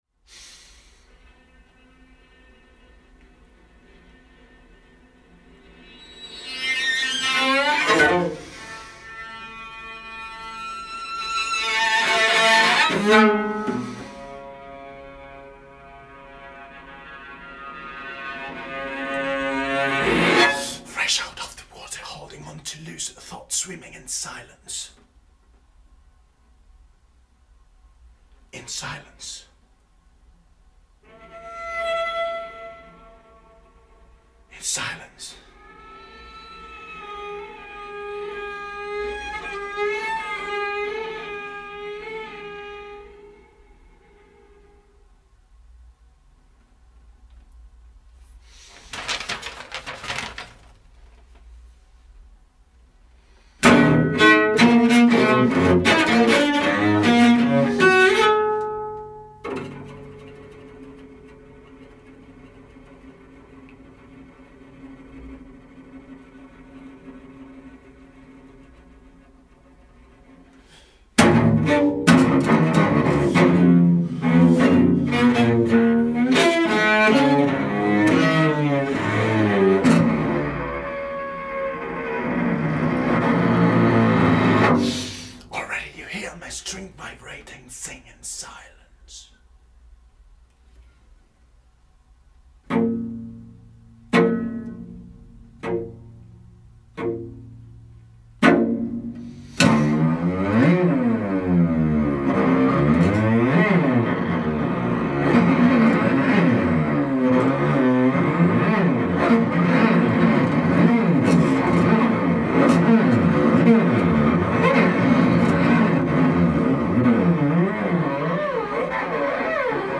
Recorded in Helsinki